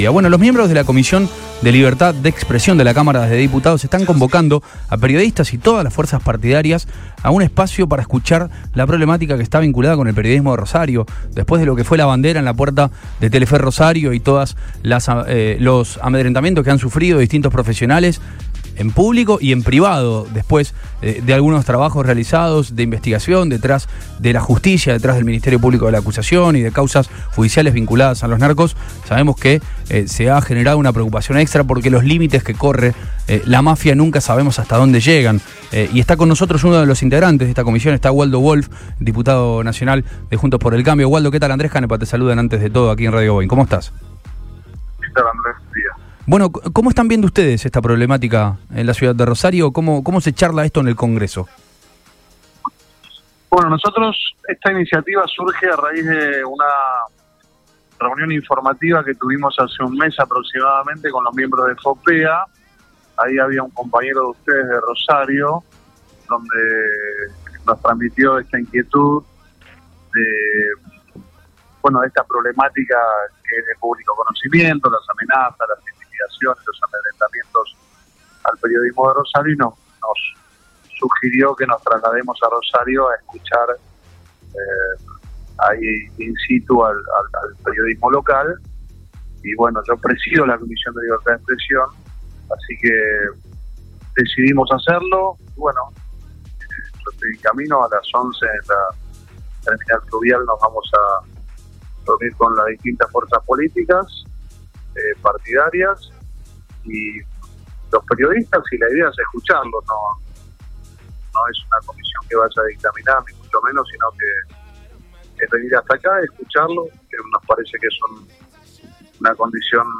En comunicación con Después de Todo de Radio Boing, el diputado nacional Waldo Wolff de Juntos por el Cambio habló sobre el tema.